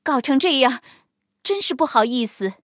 黑豹中破修理语音.OGG